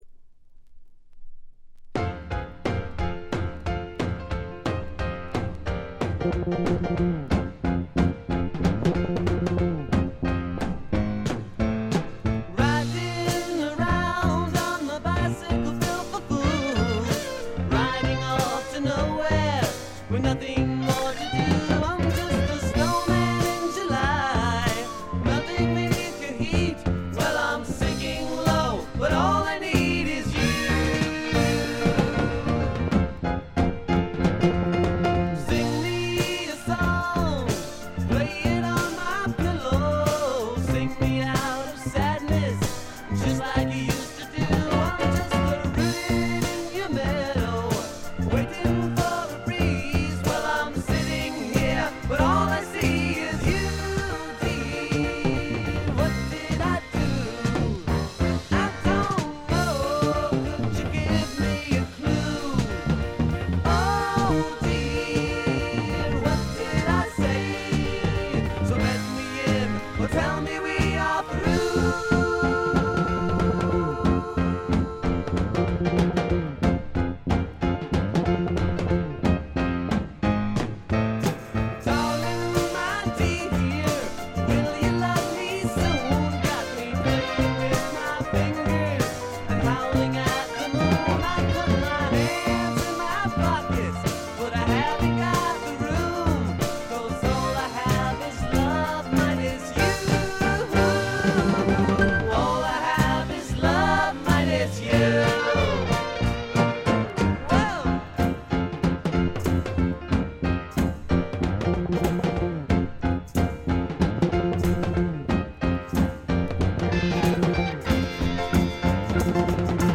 ほとんどノイズ感無し。
パワー・ポップ、ニッチ・ポップ好きにもばっちりはまる傑作です。
試聴曲は現品からの取り込み音源です。
Lead Vocals, Guitar, Piano